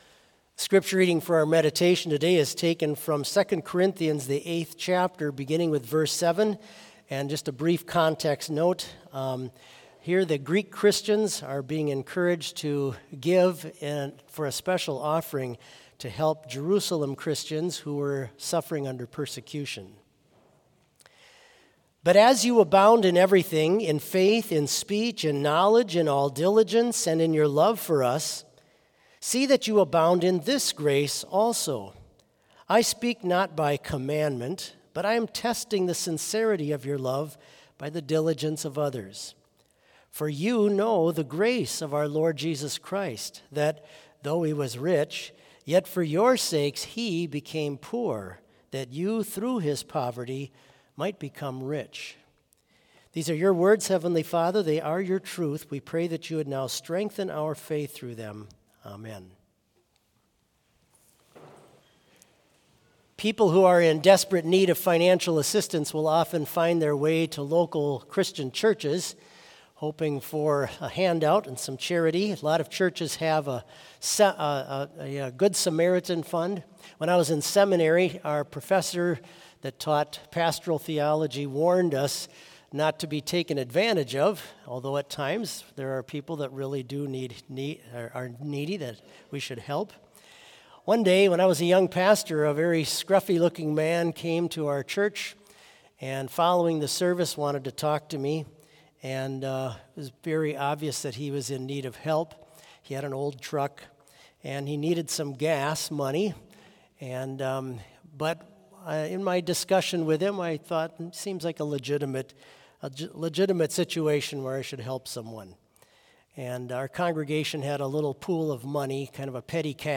Complete service audio for Chapel - Monday, August 26, 2024